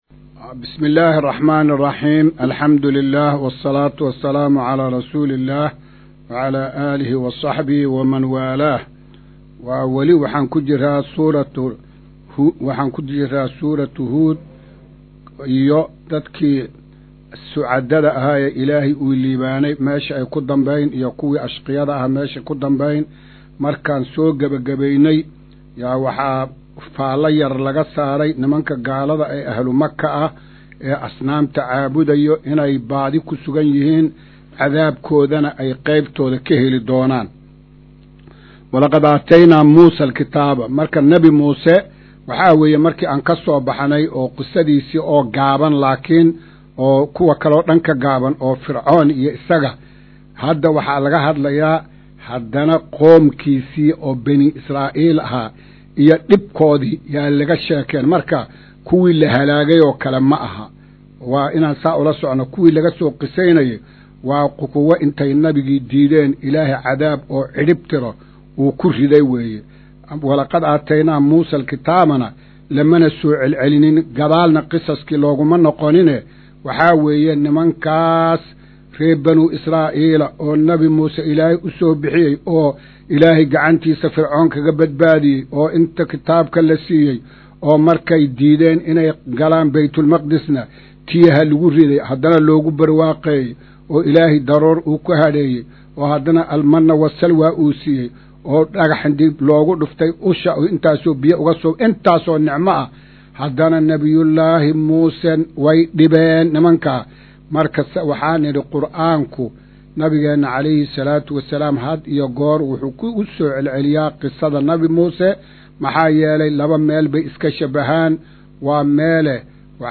Maqal:- Casharka Tafsiirka Qur’aanka Idaacadda Himilo “Darsiga 116aad”